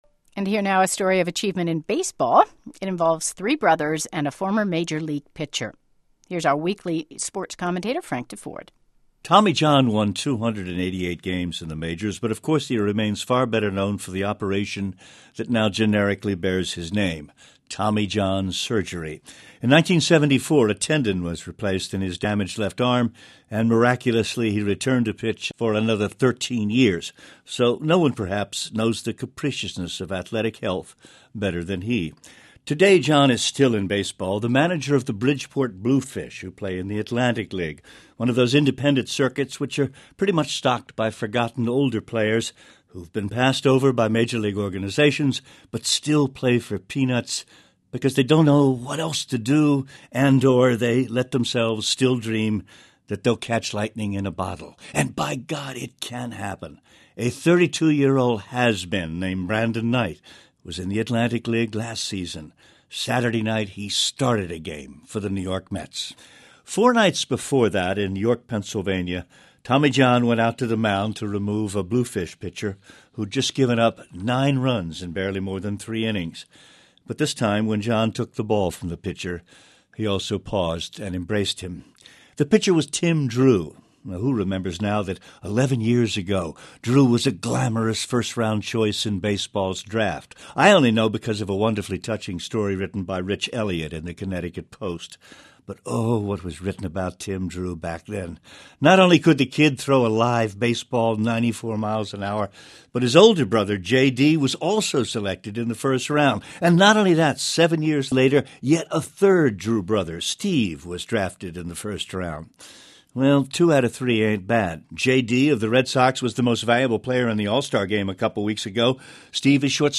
NPR Morning Edition's Frank Deford gives weekly commentary on a cross section of the world of sports.